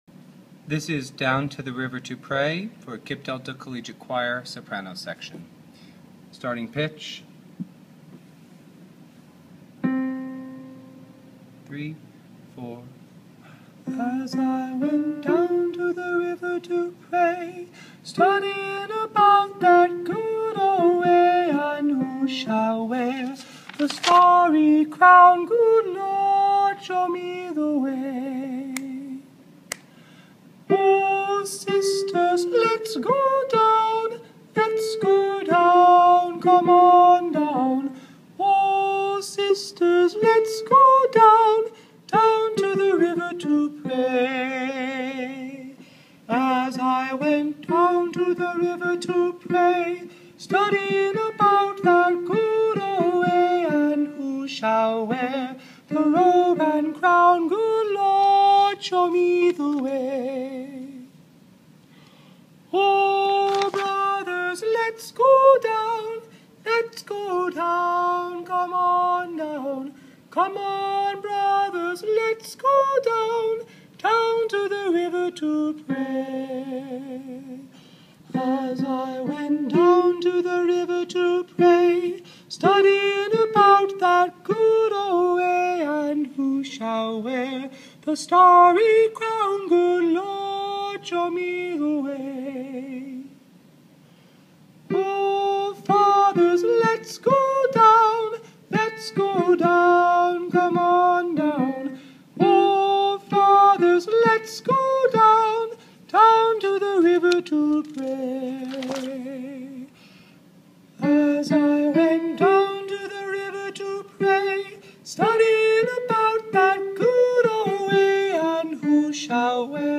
Down to the river to pray – Sopran
Down-to-the-River-to-Pray-Soprano.mp3